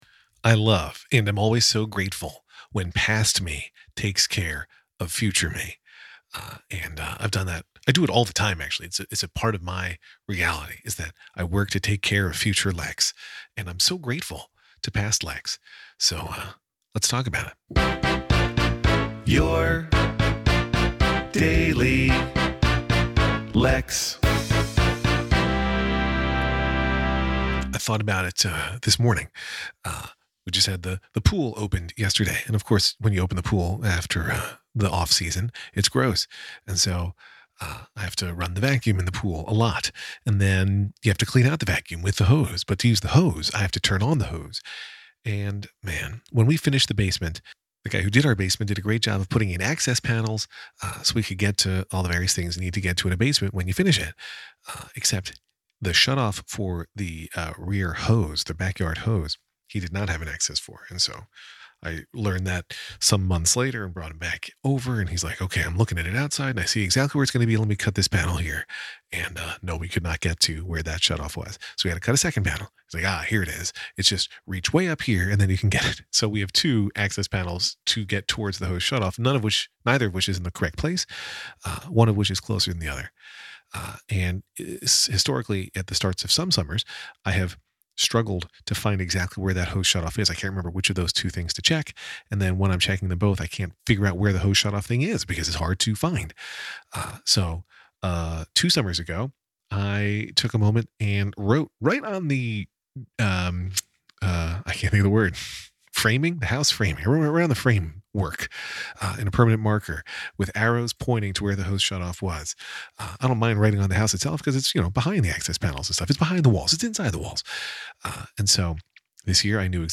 Past me takes care of future me. And here’s a Logic session musician sampler.